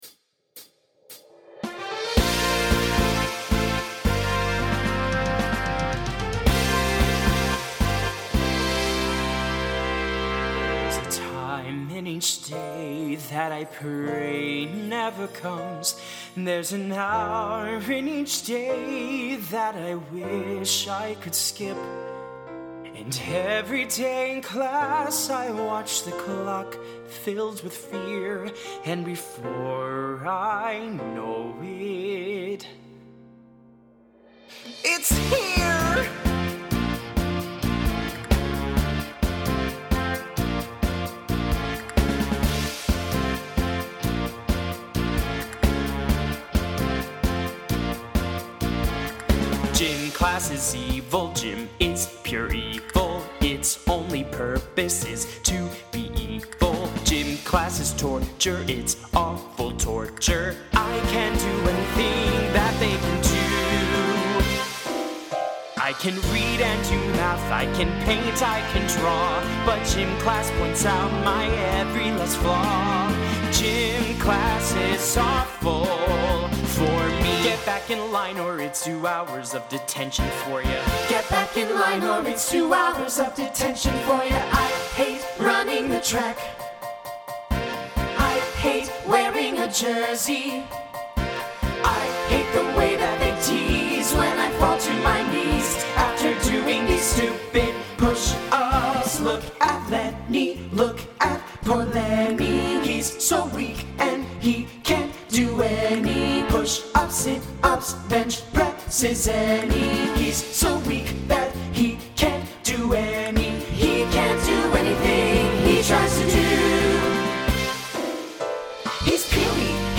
Voicing SATB Instrumental combo Genre Broadway/Film